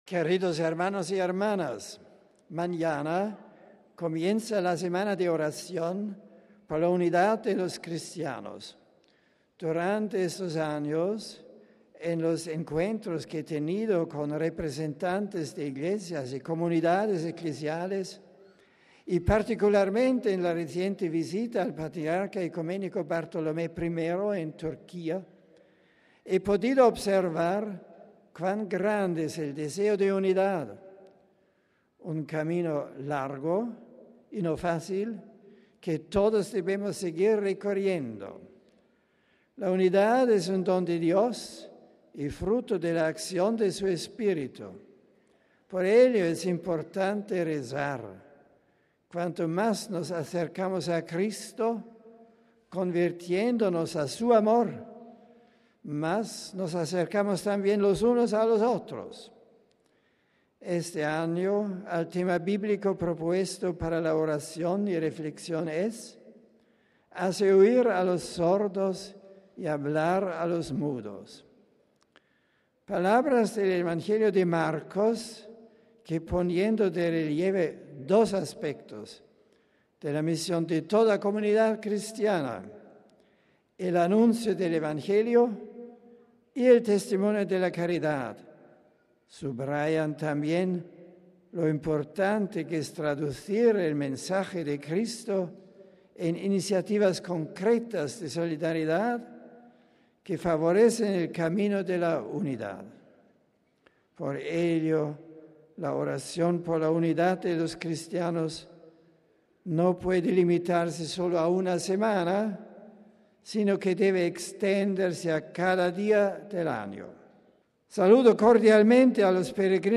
Este ha sido el resumen que de su catequesis ha hecho Benedicto XVI en español, saludando a los peregrinos de España y de América Latina: RealAudio